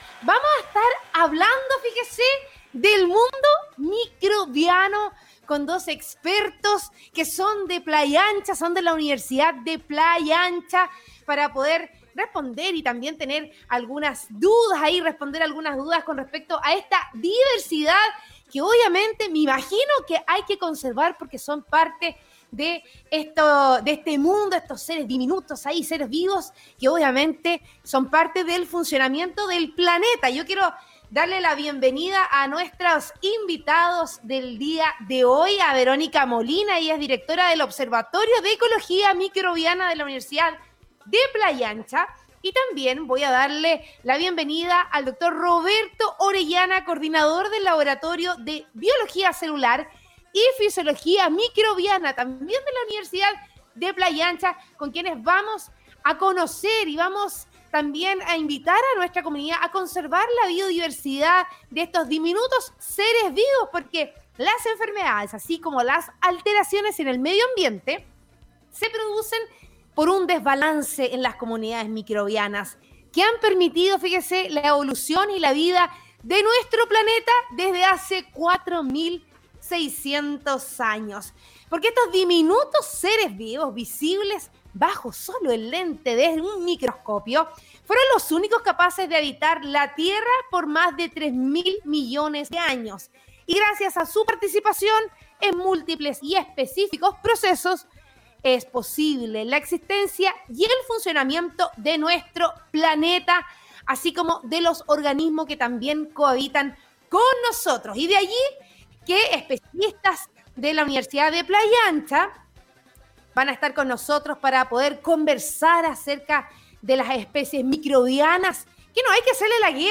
La conversación en Radio Congreso giró en torno a antecedentes entregados en una información de UPLA Noticias .